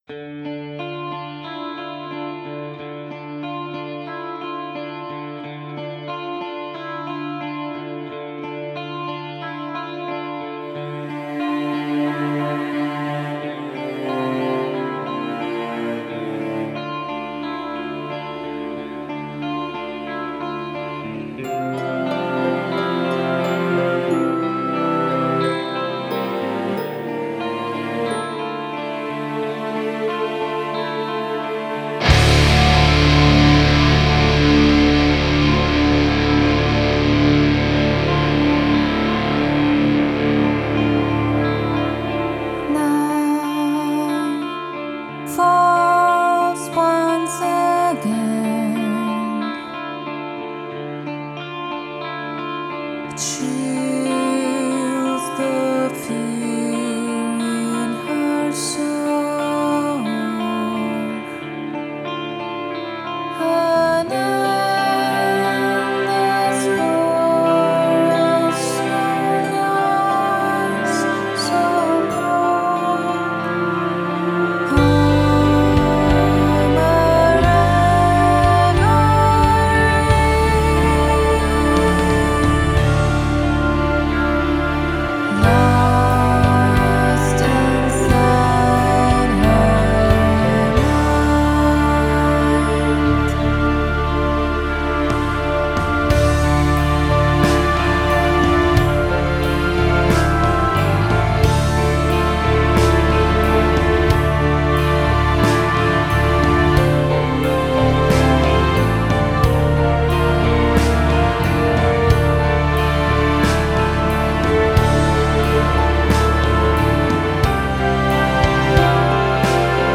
Альтернативная музыка Gothic Metal